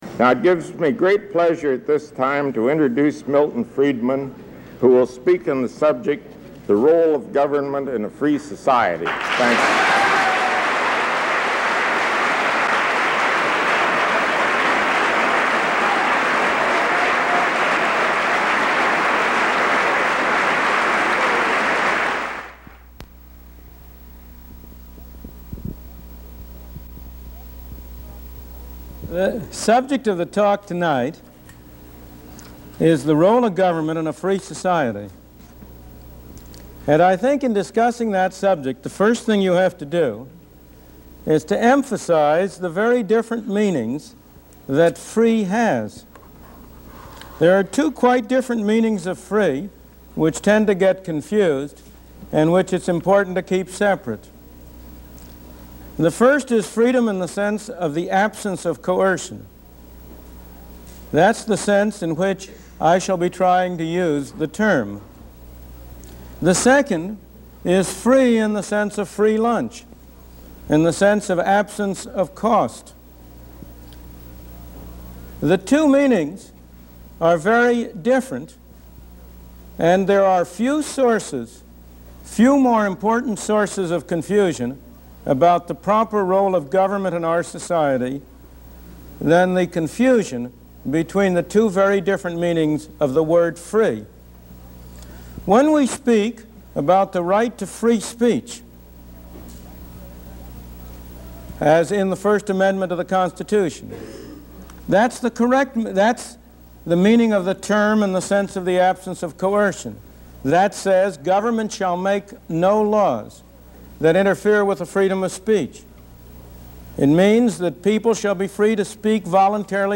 Episode 4 - Milton Friedman Speaks - The Role of Government in a Free Society